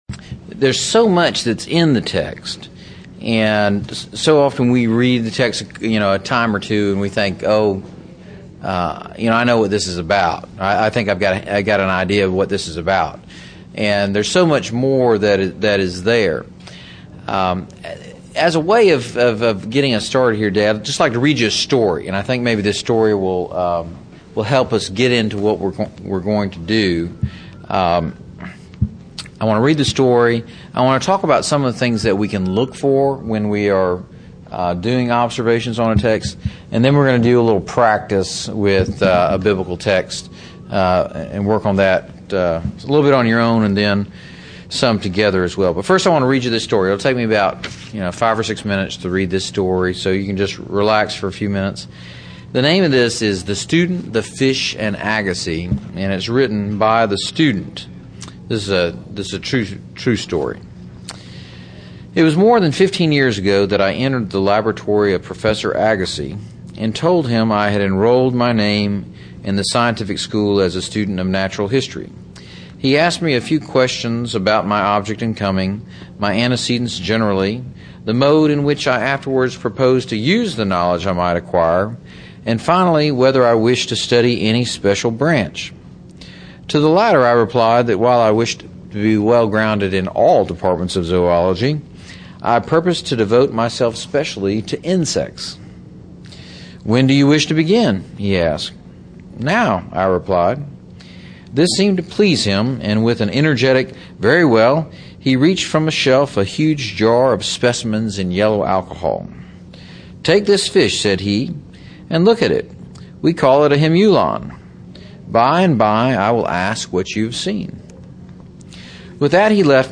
Address: Observation Skills: Laying an Essential Foundation for Biblical Interpretation Recording Date